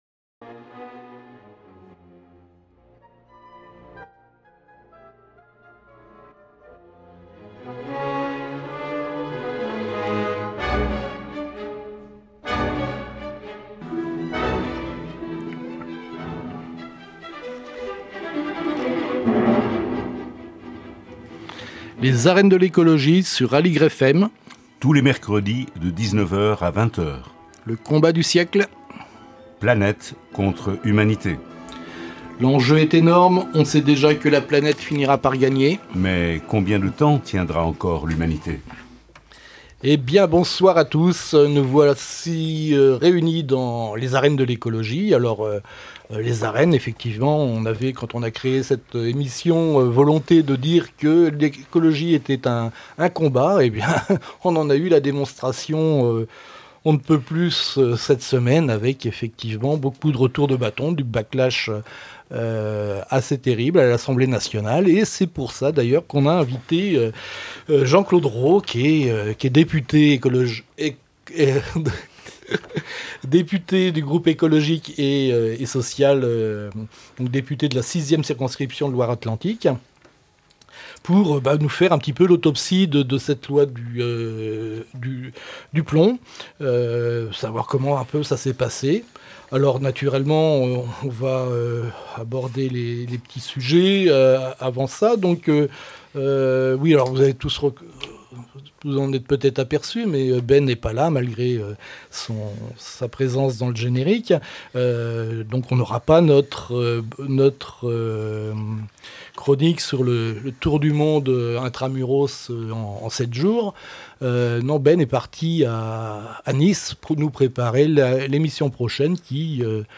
L'autopsie du hold-up à l'assemblée nationale, le vrai faux rejet de la loi Duplomb Les arènes de l'écologie #24 - émission du 4 juin 2025 Dans cet épisode, Les Arènes de l’écologie accueillent Jean-Claude Raux, professeur de lettres et d’anglais dans un lycée professionnel de Redon et député écoloiste de la 6ème circonscription de la Loire-Atlantique (44).